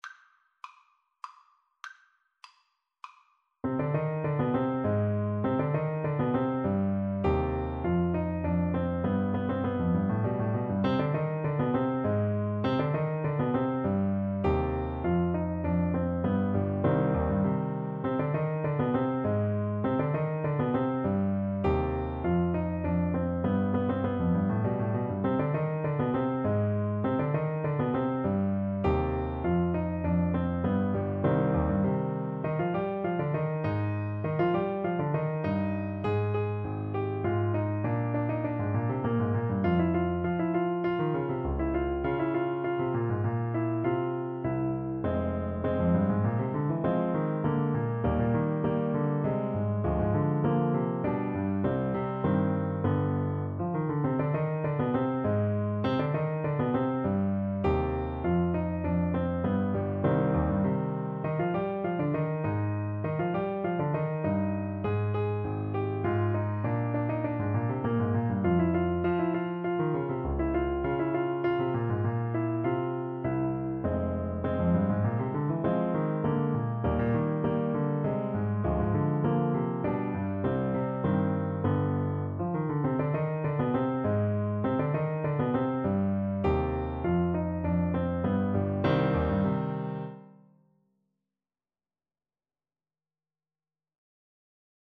3/4 (View more 3/4 Music)
Allegretto = 100
Classical (View more Classical Saxophone Music)